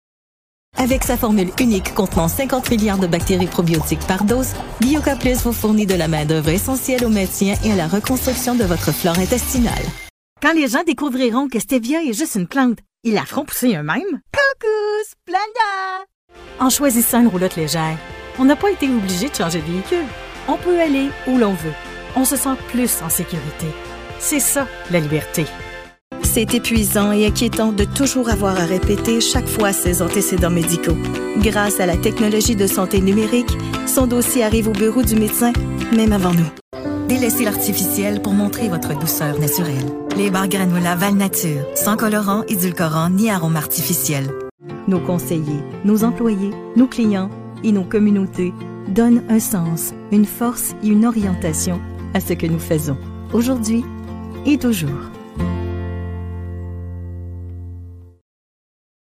Commercial demo (FR)
French - Canadian